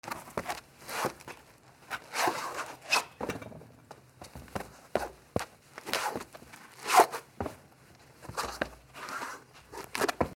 / M｜他分類 / L01 ｜小道具
塀をよじ登る